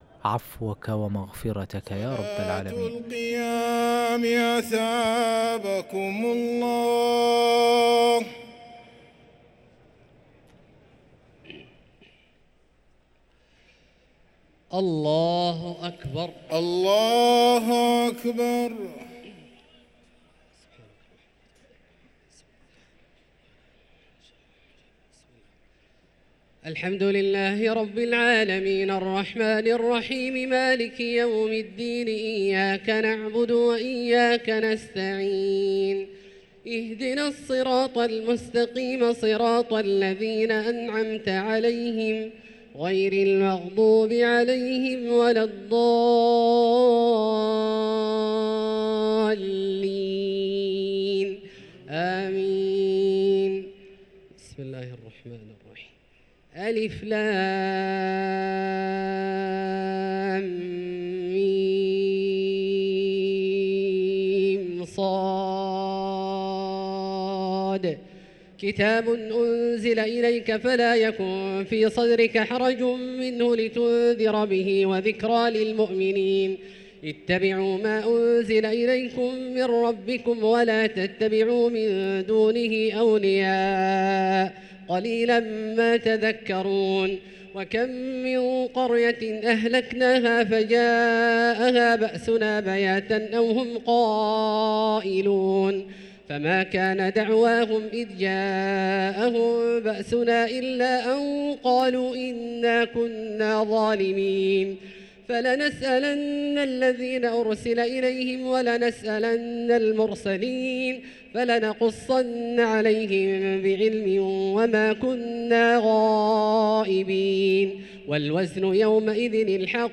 صلاة التراويح ليلة 11 رمضان 1444 للقارئ عبدالله الجهني - الثلاث التسليمات الأولى صلاة التراويح